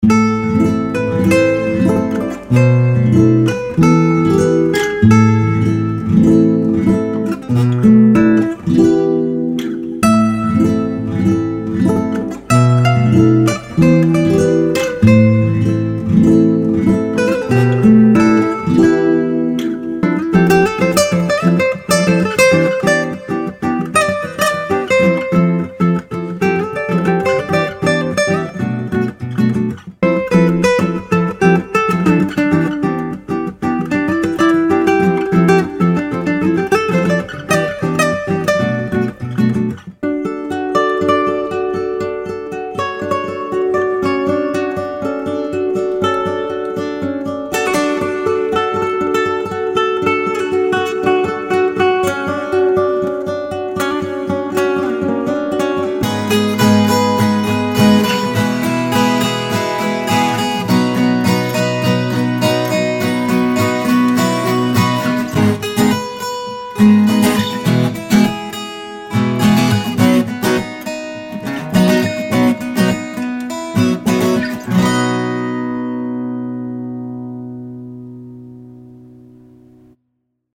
A collection of soothing and rhythmic acoustic guitar riffs.
Mellow-Acoustic-Guitars-Vol-4.mp3